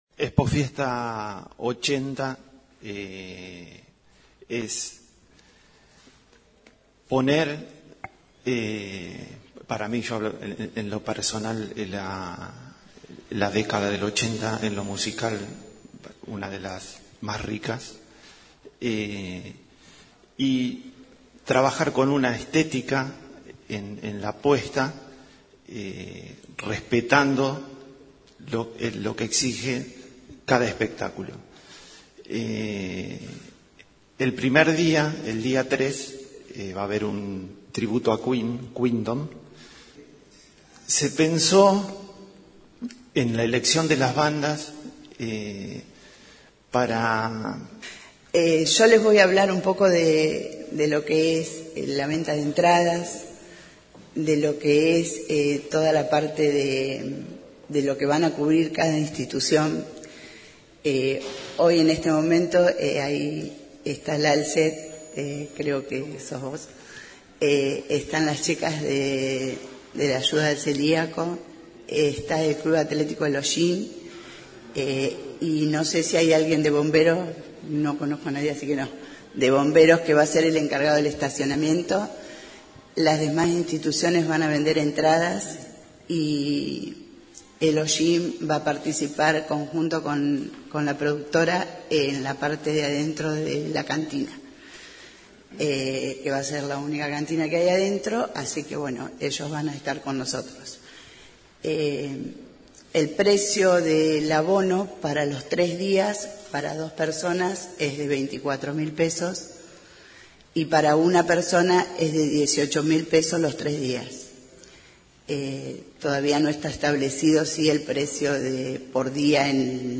Se realizó este viernes en el salón rojo municipal una conferencia de prensa donde se presentó el evento que se realizará en el Parque Plaza Montero los días 3, 4 y 5 de enero de 2025. El intendente Alberto Gelené e integrantes de la organización brindaron a los medios los detalles de este acontecimiento que reunirá diferentes propuestas y atractivos.